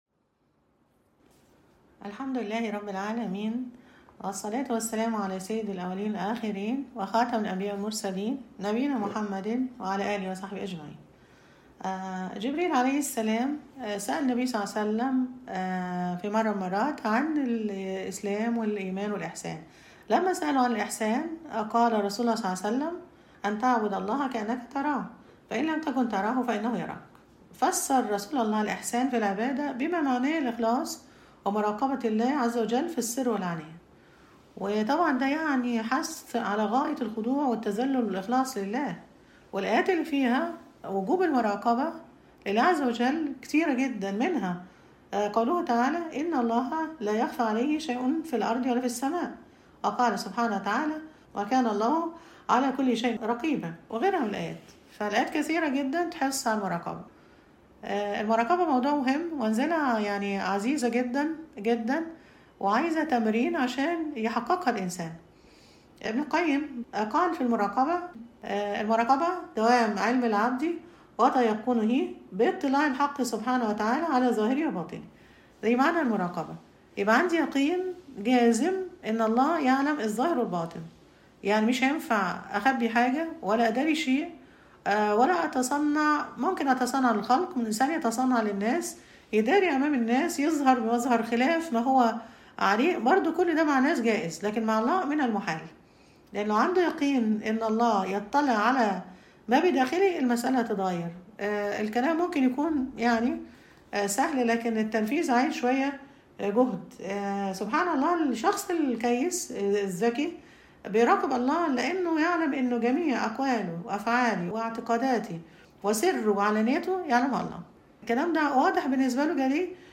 المحاضرة السادسة_”المراقبة”
حديث-بداية-الهداية-المحاضرة-السادسة-المراقبة-احتياج-العبد-لمراقبة-الله.mp3